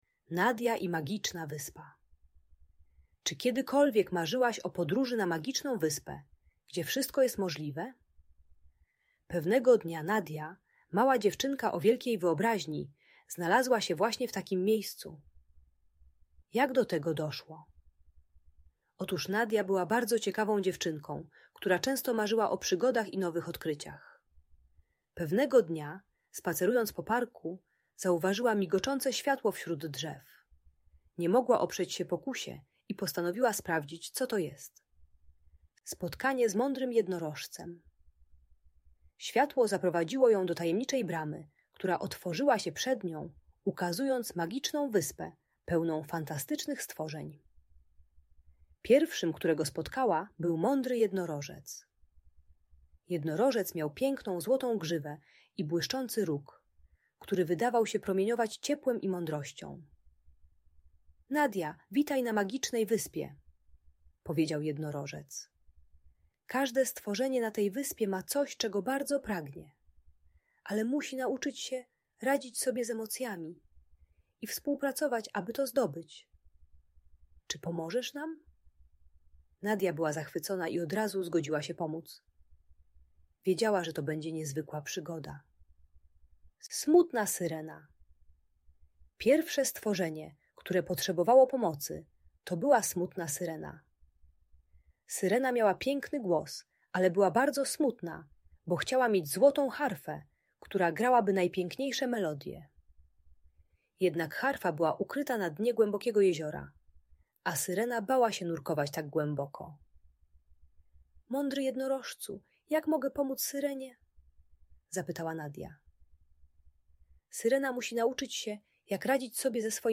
Nadia i Magiczna Wyspa - Niepokojące zachowania | Audiobajka